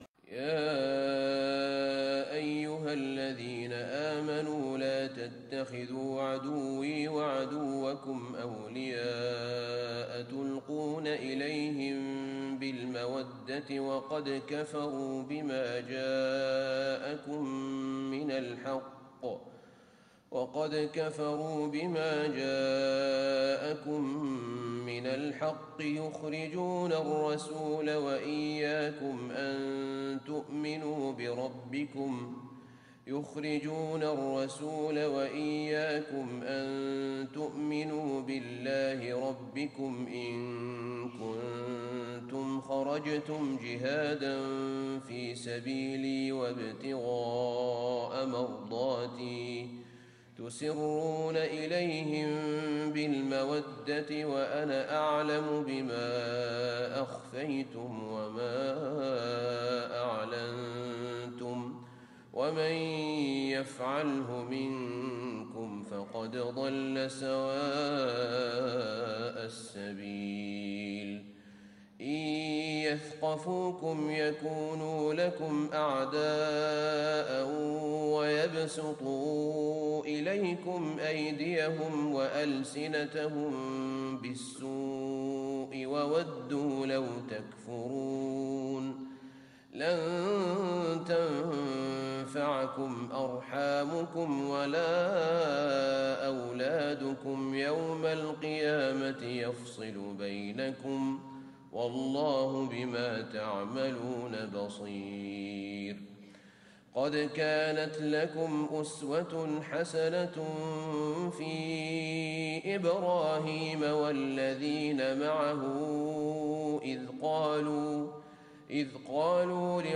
صلاة الفجر 20 ربيع الأول 1437هـ سورة الممتحنة > 1437 🕌 > الفروض - تلاوات الحرمين